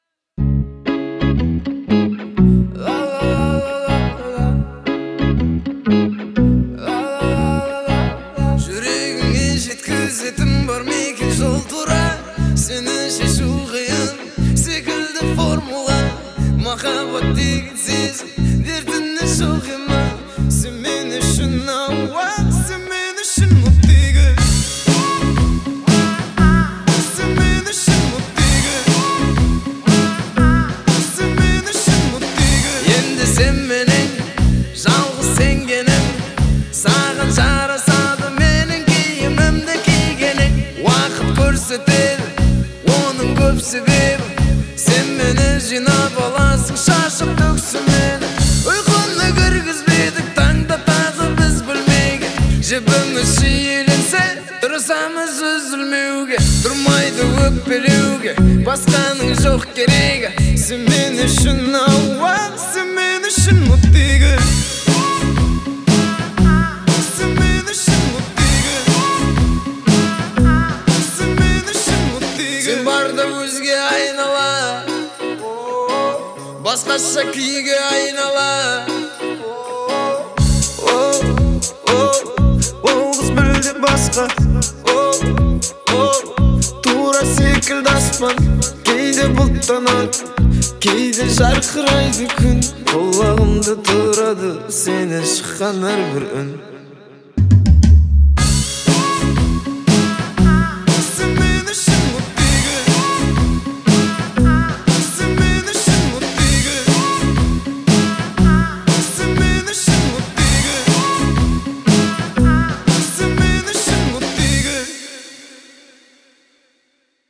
которая сочетает в себе элементы поп и электронной музыки.